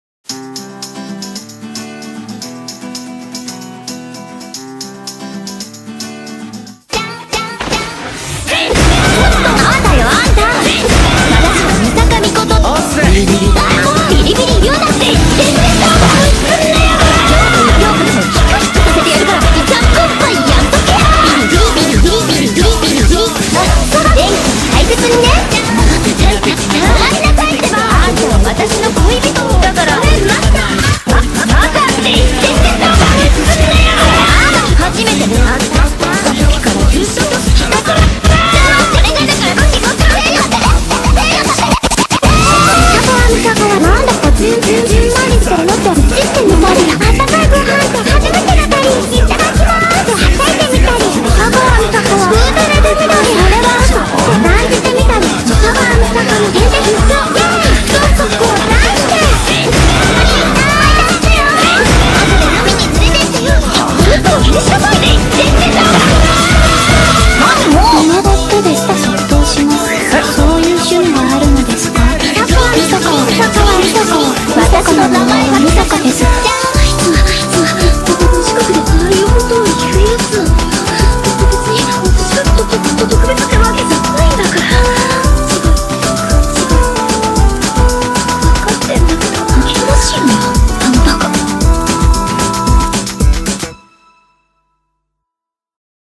applause.mp3